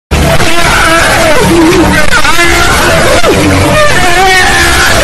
Play, download and share Plankton Cringing original sound button!!!!
plankton-cringing.mp3